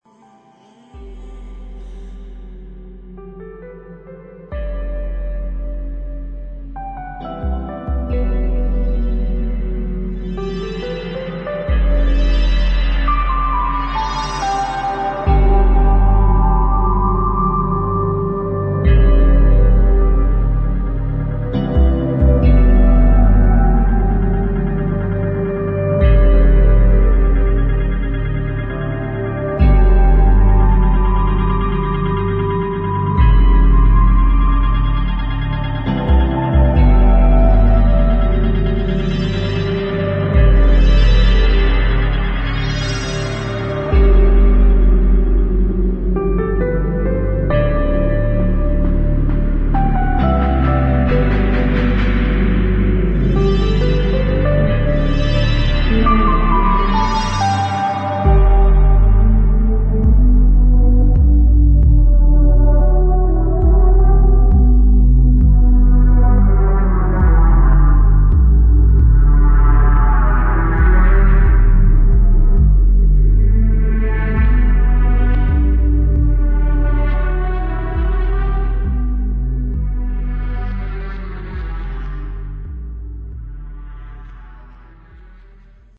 [ BASS / TECHNO / ELECTRONIC ]